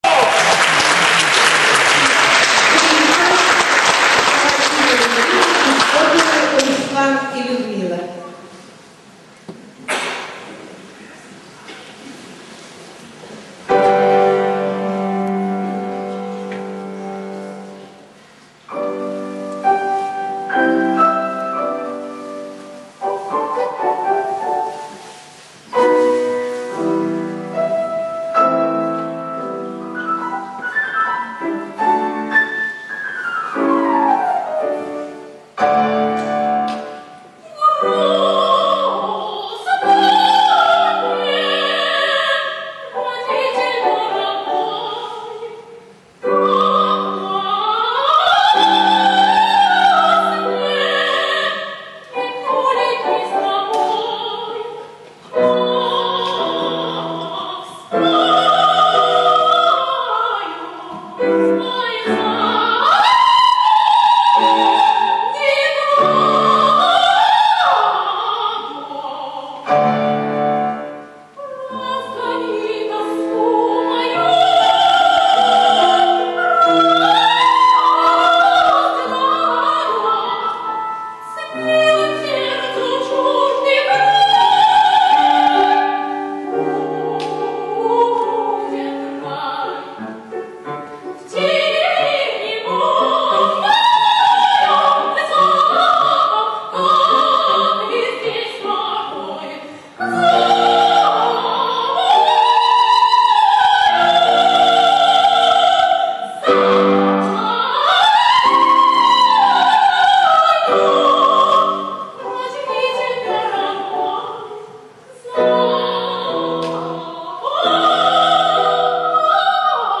Архив концертных выступлений